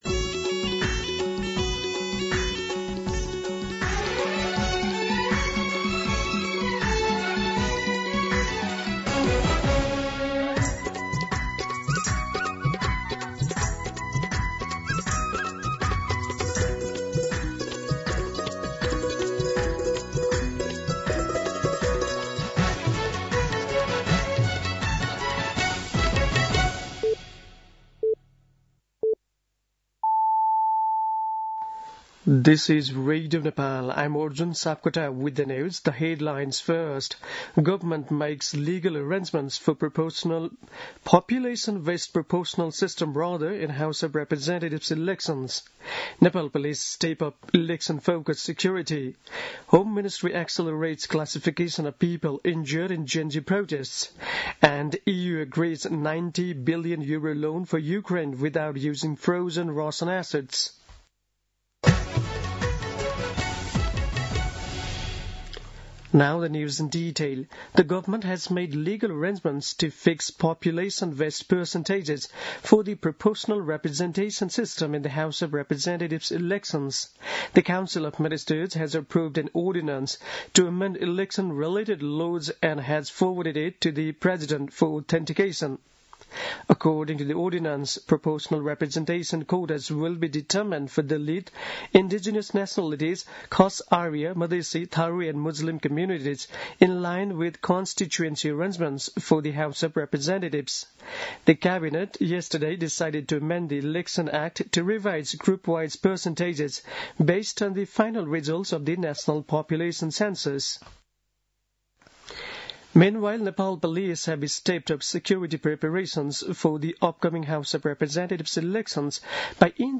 दिउँसो २ बजेको अङ्ग्रेजी समाचार : ४ पुष , २०८२